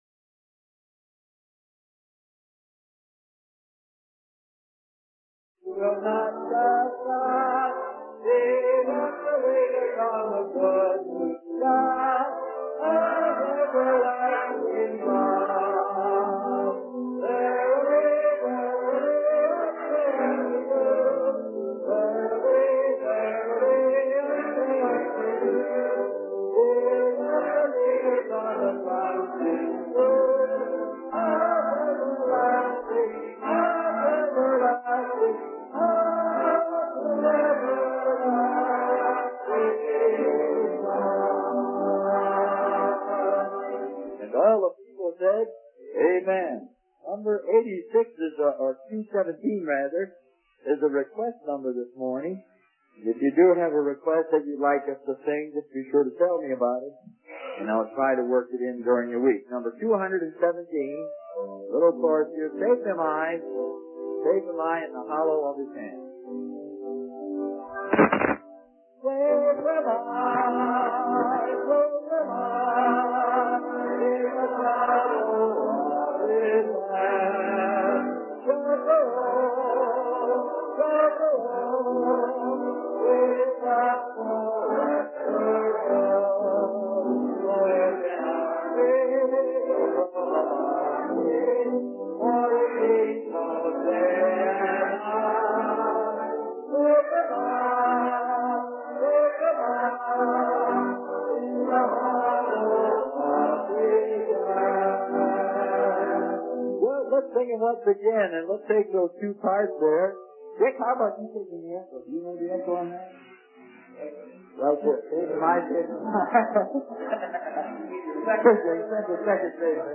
The sermon concludes with a song that speaks of finding strength and trust in Jesus through all trials and difficulties.